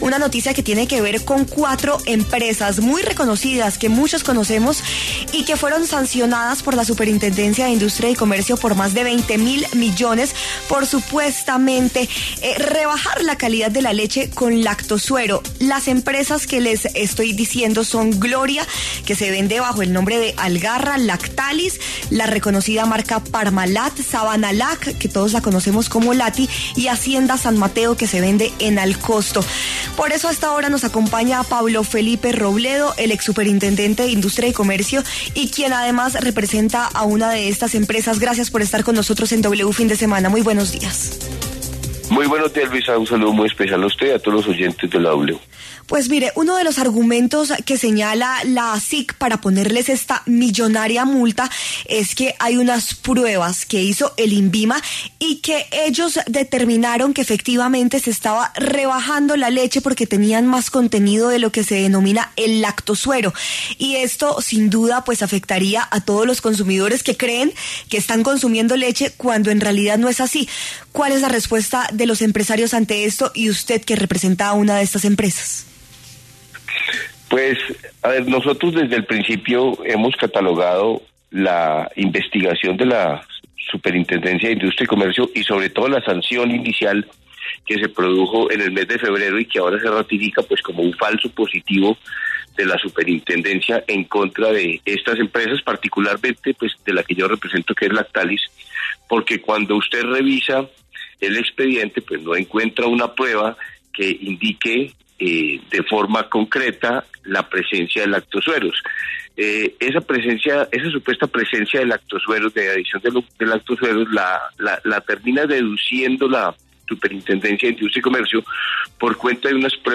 W Fin De Semana conversó con Pablo Felipe Robledo, abogado, exsuperintendente de Industria y Comercio y apoderado de Lactalis Parmalat, una de las compañías productoras de leche que fueron sancionadas por la SIC por contener lactosuero en sus productos.